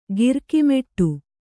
♪ girki meṭṭu